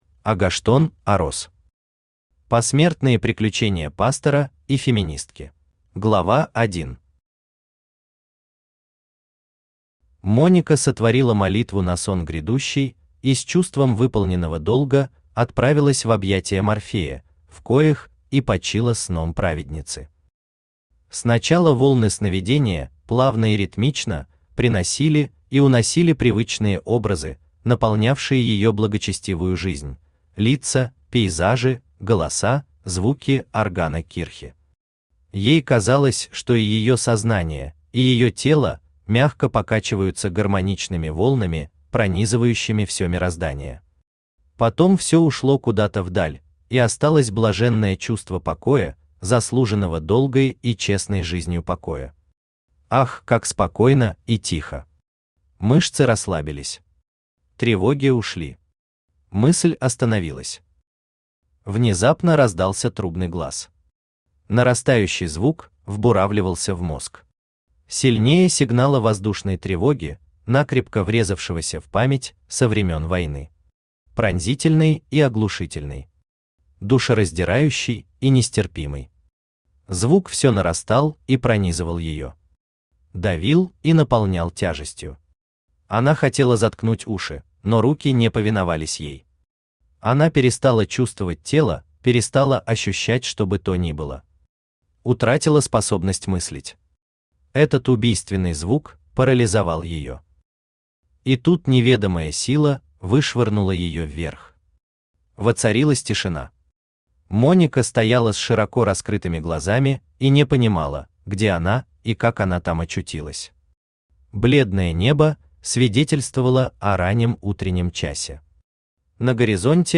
Аудиокнига Посмертные приключения пастора и феминистки | Библиотека аудиокниг
Aудиокнига Посмертные приключения пастора и феминистки Автор Агоштон Орос Читает аудиокнигу Авточтец ЛитРес.